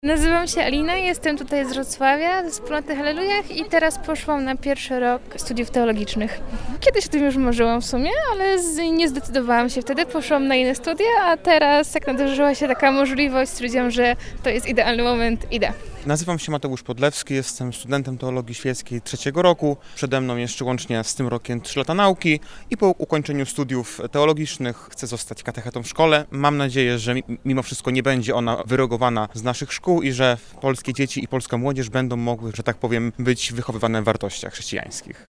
Zapytaliśmy studentów o ich aspiracje.
02_studenci.mp3